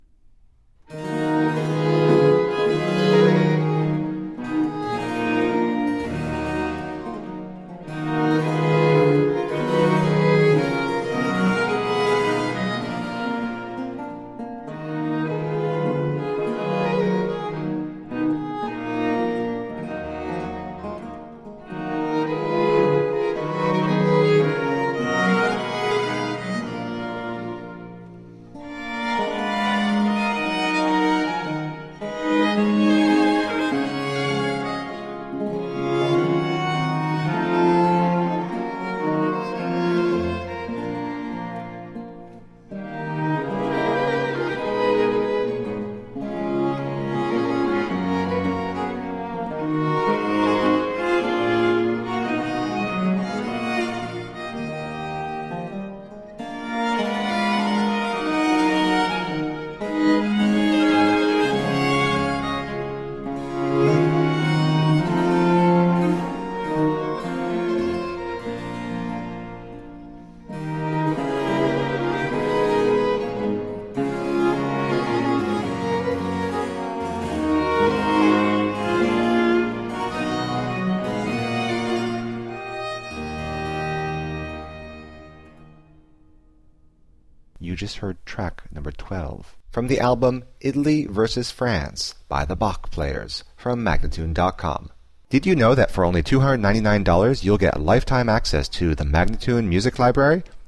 Baroque instrumental and vocal gems.
Classical, Chamber Music, Baroque, Instrumental
Harpsichord